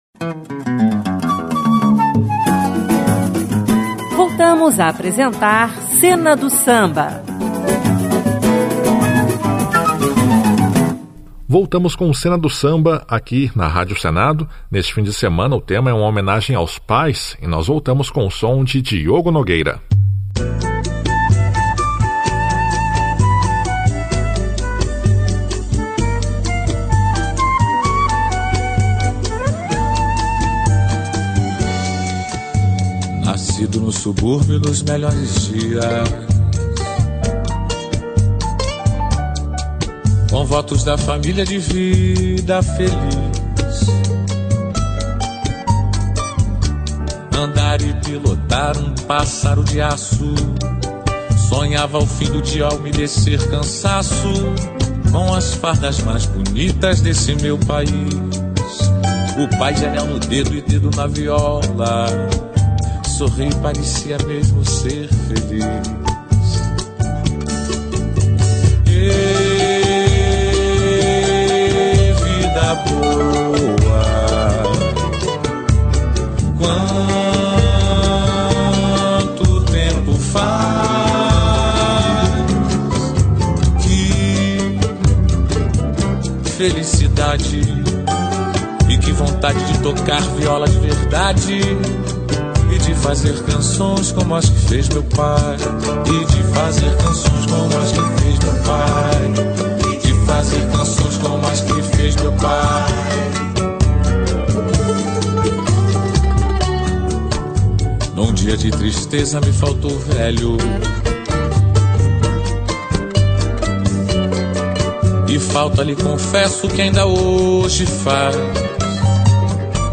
Sambas clássicos de carnaval